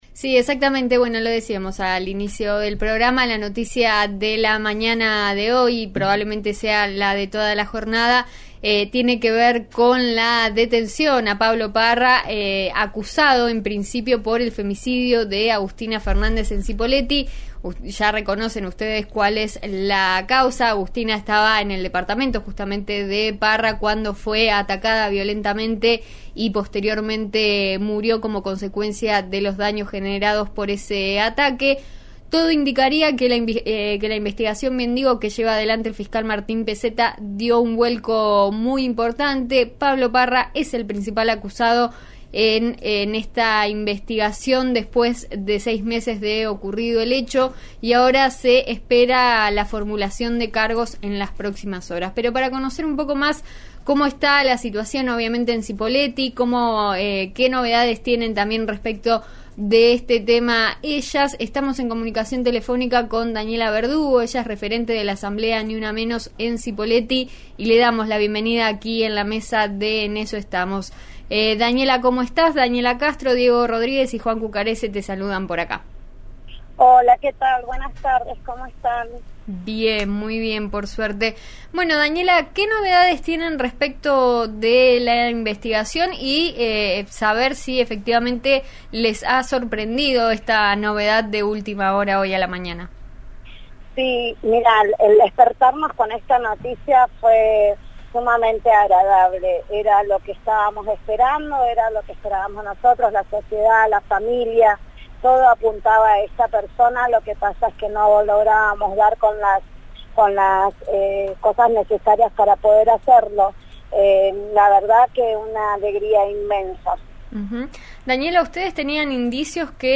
expresó en dialogo con RÍO NEGRO